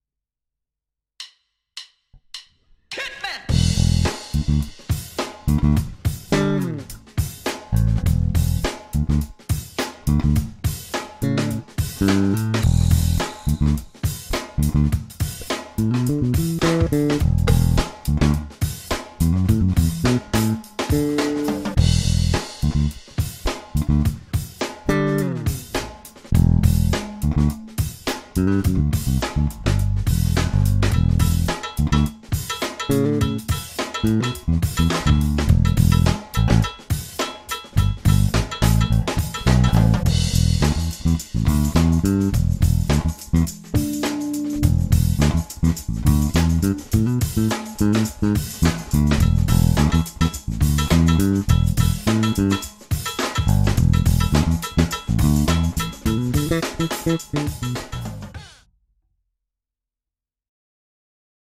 Ukázka 1 - oba snímače, treble boost 10%, bass boost 20%